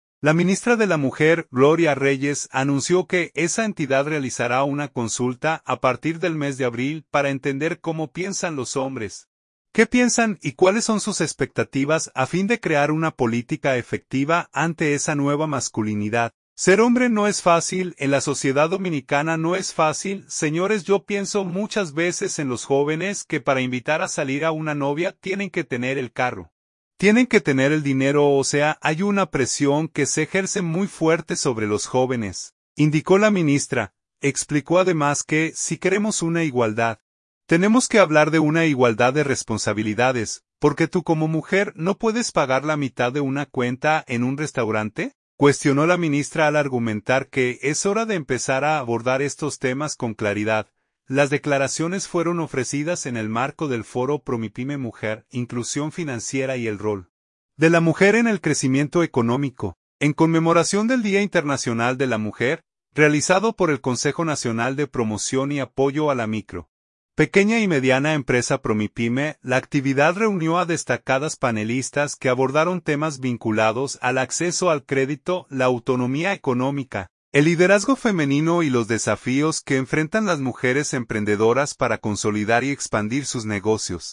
Las declaraciones fueron ofrecidas en el marco del foro “Promipyme Mujer: Inclusión financiera y el rol de la mujer en el crecimiento económico”, en conmemoración del Día Internacional de la Mujer, realizado por el Consejo Nacional de Promoción y Apoyo a la Micro, Pequeña y Mediana Empresa (Promipyme).